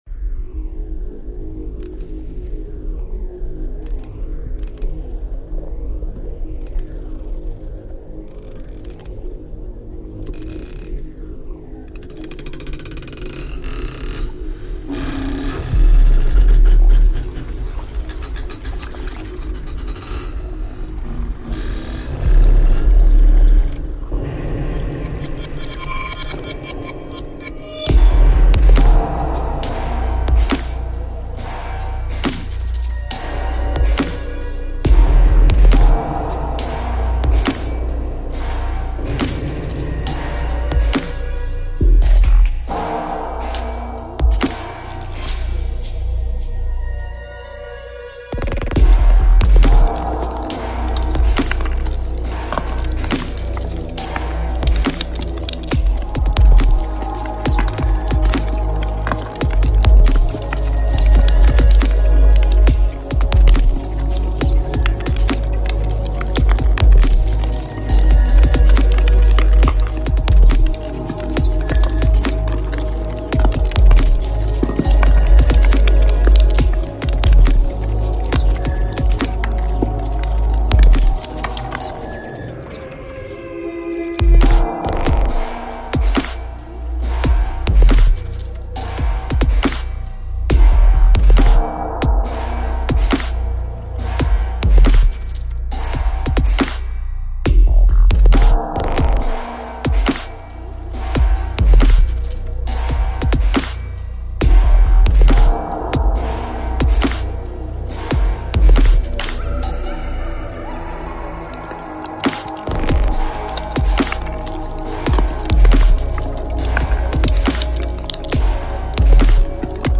That disquieting grey noise is a smoke.